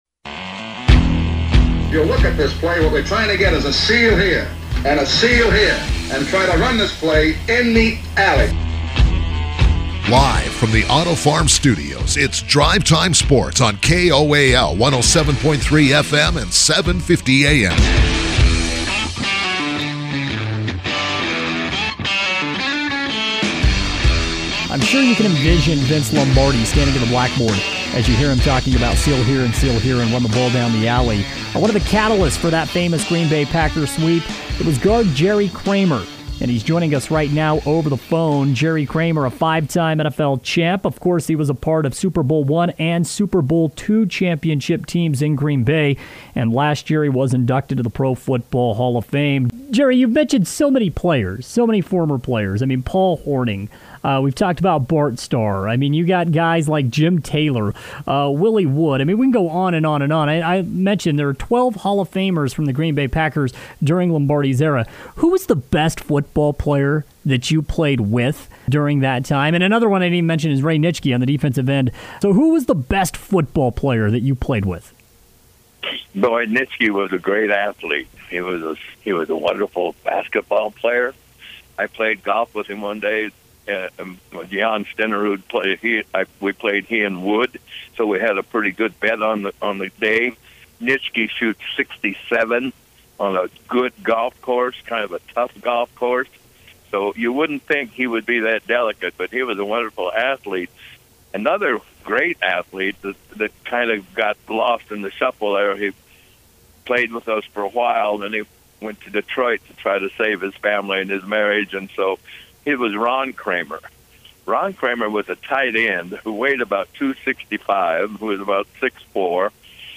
Jerry Kramer joined KOAL's Drive Time Sports Wednesday afternoon to share stories from his legendary career under Vince Lombardi, which included wins in Super Bowl 1 and 2, five total NFL championships and a Hall of Fame induction in 2018.